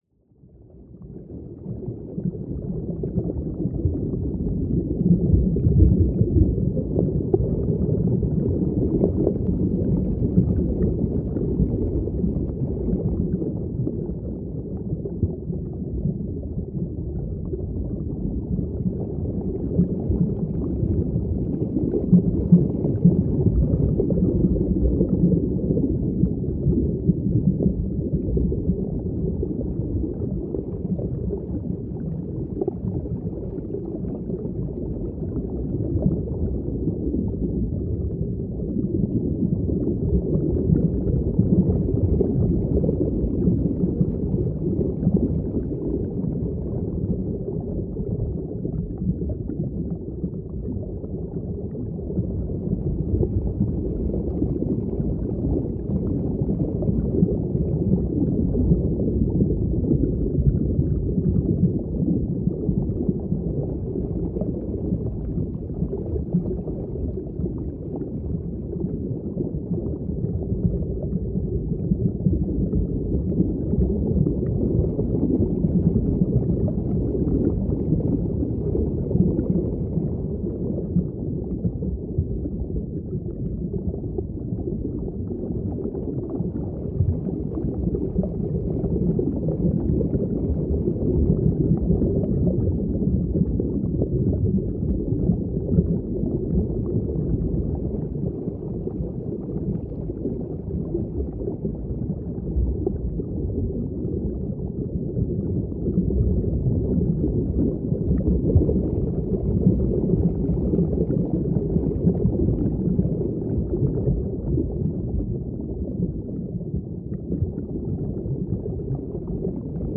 Underwater_Designed_Ambience_Abyss.ogg